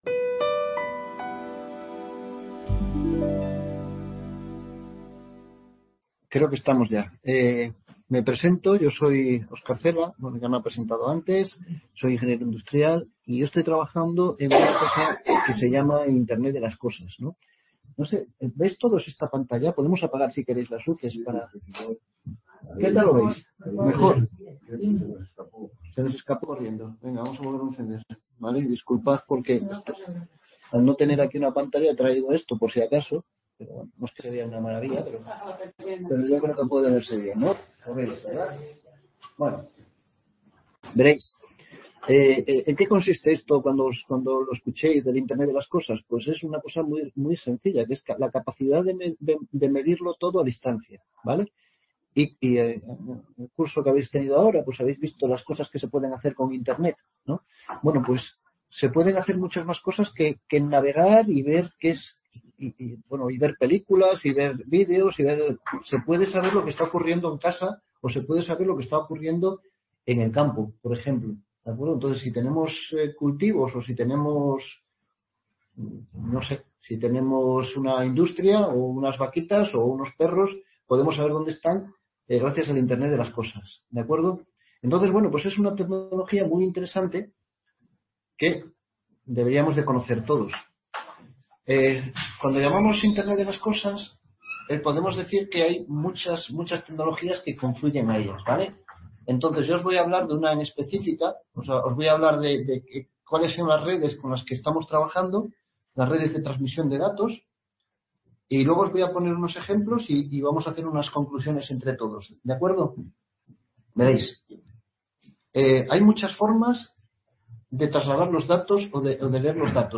Aula de PONFERRADA: Conferencias y talleres